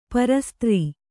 ♪ para strī